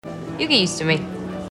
【マイインターン】のアンハサウェイのセリフ。
ゆーぎーすとぅみ？？？